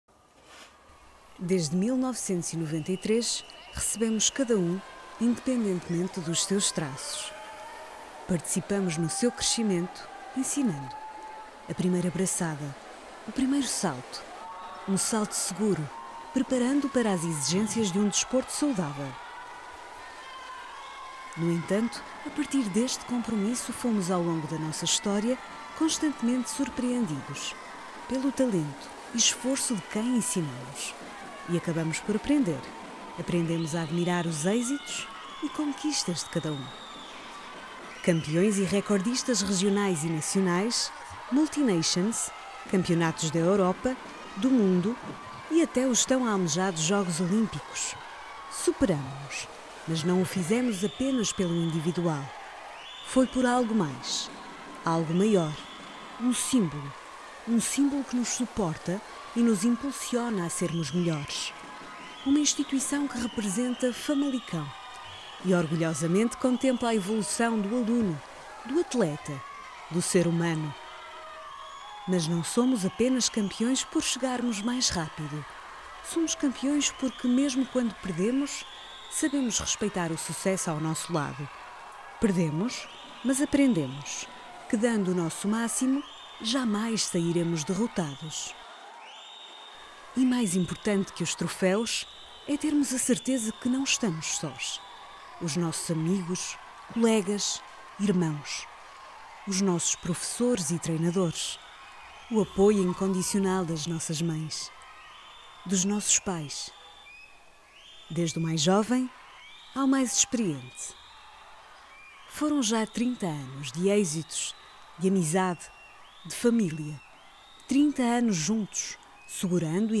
Portuguese Female Voiceover
Corporate Video
A corporate or institutional video presents content in a formal, assertive, slow, and thoughtful way.